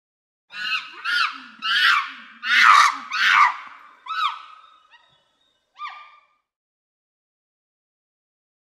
Monkey ( Unknown ) Scream. Series Of Raspy Screams With Snort Inhales. Close Perspective.